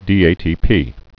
(dēātēpē)